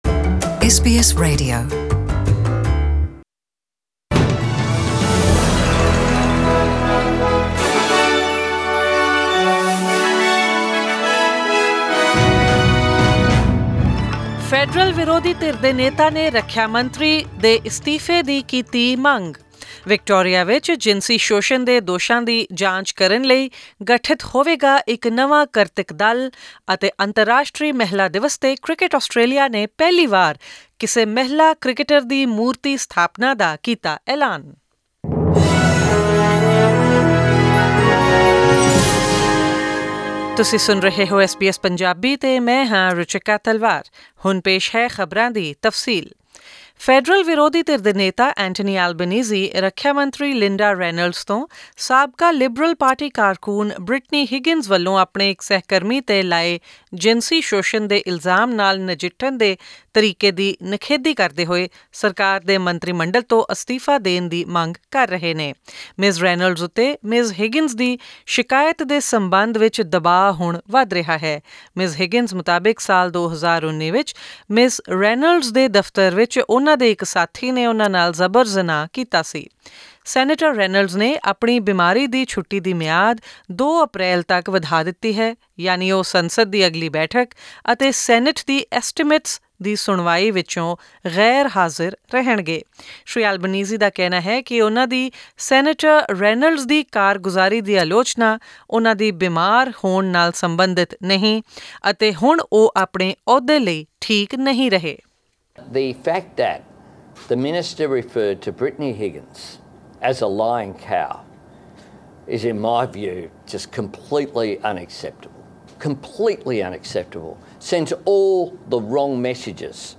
The newly-formed Recognition of Women in Cricket Working Group will decide which female Australian cricketer will be immortalised in bronze soon at SCG. Tune into the bulletin for more local and international news, updates on sports and forex, and tomorrow's weather forecast.